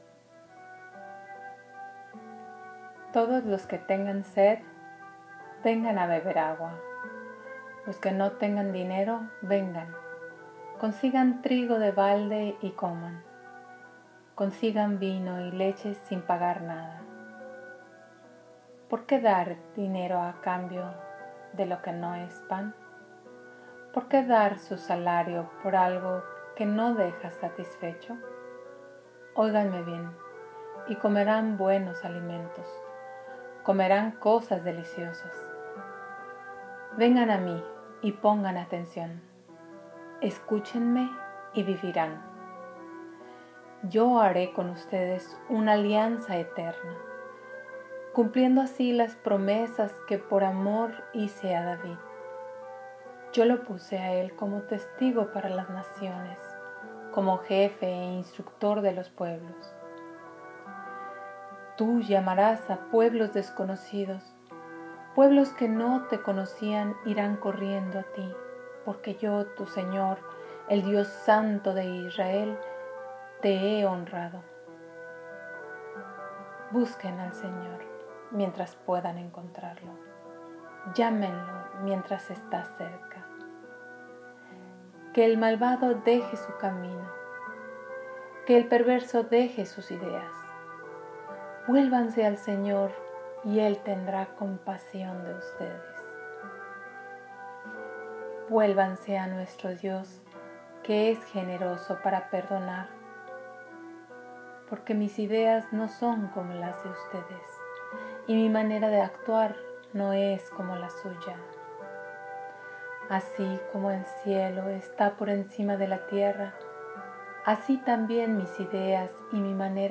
Lectura meditada